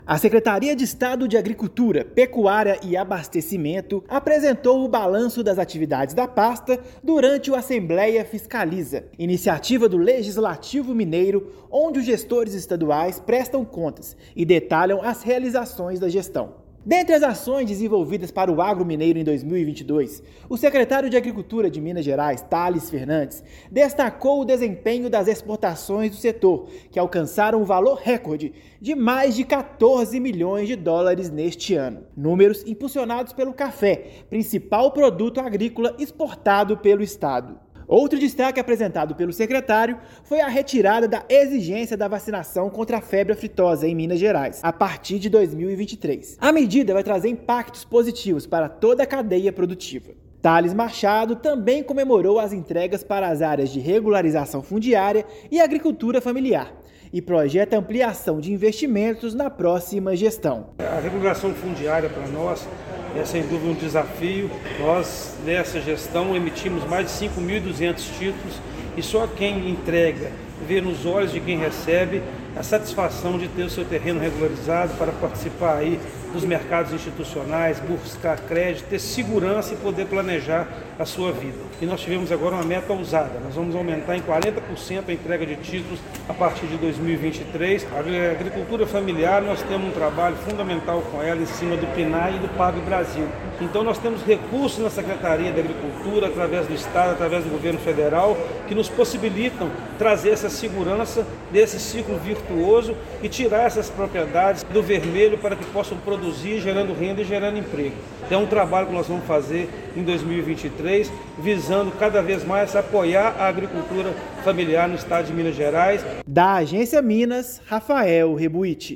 Secretaria de Agricultura destaca, também, a retirada da campanha de vacinação contra febre aftosa no estado a partir de 2023. Ouça matéria de rádio.